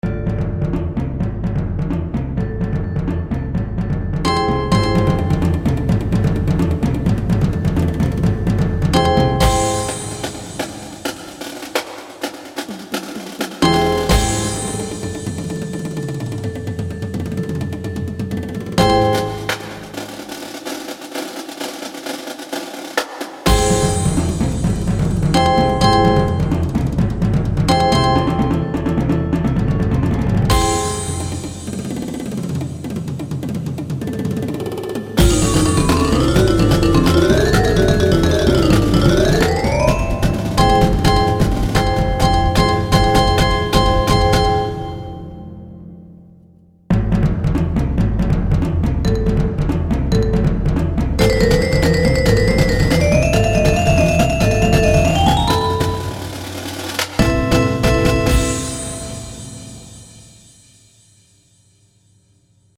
• Snares
• Quads (5 or 6 drums)
• 5 Bass Drums
• Hand Cymbals
• Bells/Chimes
• Crotales/Bells
• Xylophone
• 2 Vibraphones
• 2 Marimbas
• Timpani
• 2 Synthesizers
• Bass Guitar
• 2 Percussion